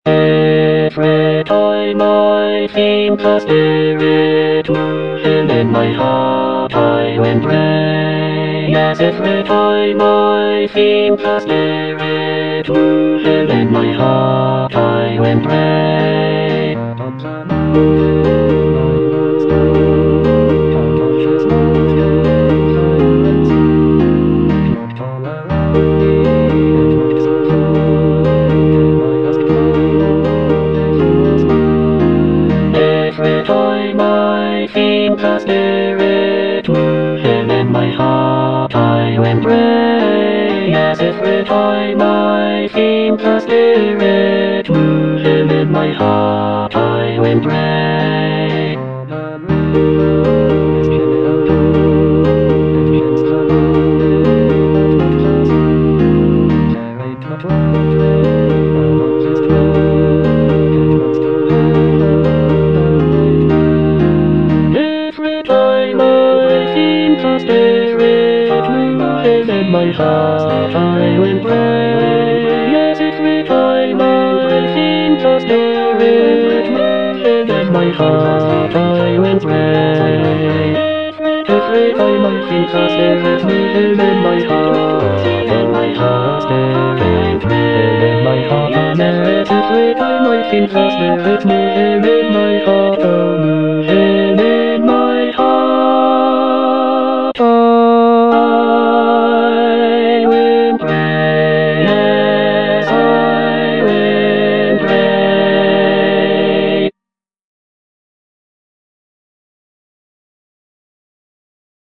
Bass I (Emphasised voice and other voices)